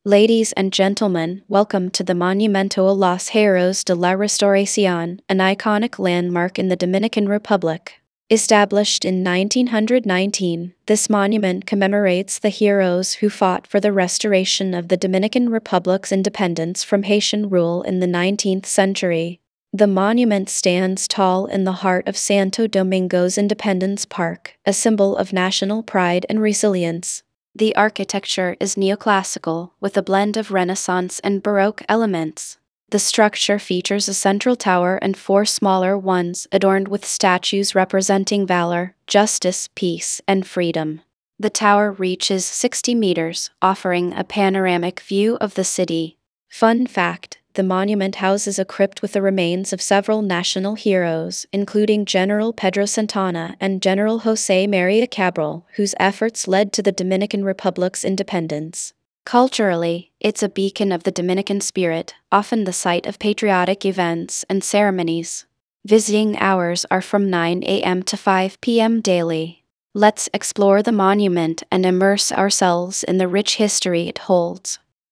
karibeo_api / tts / cache / 15e08fb6b6875c5c36c678ef526d27e7.wav